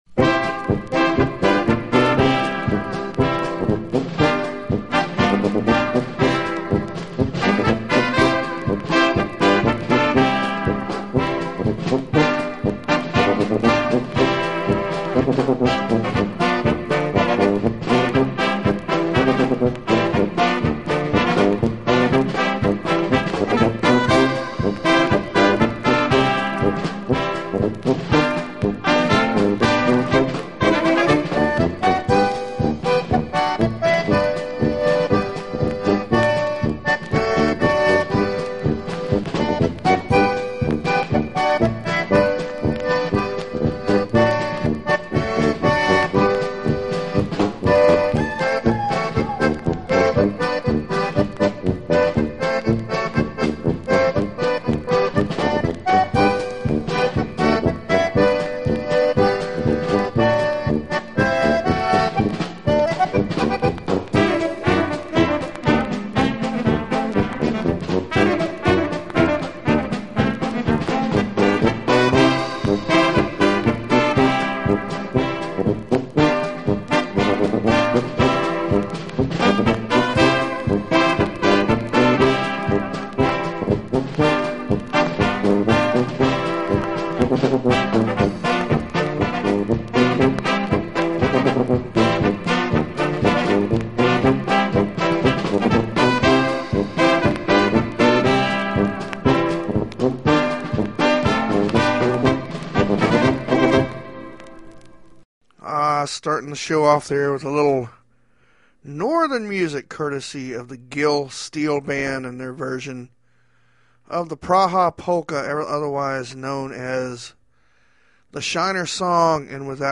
Polka 2.
Waltz
Commentary 8.